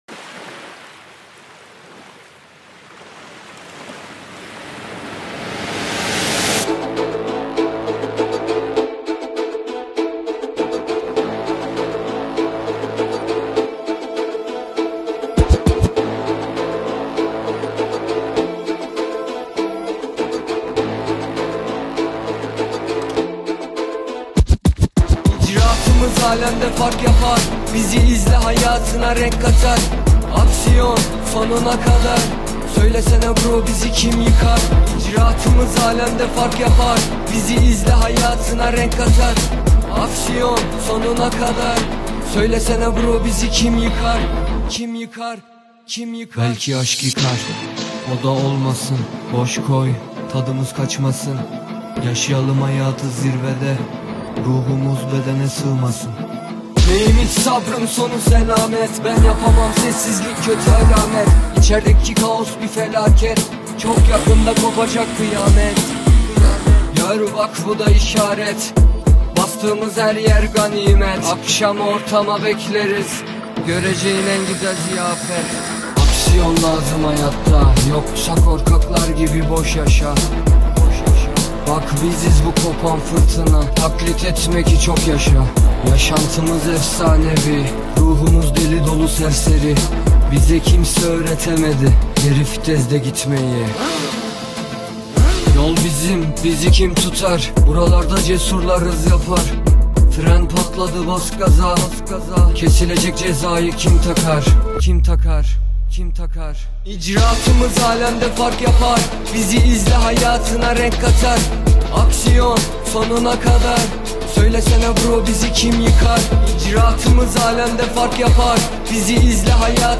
Турецкие песни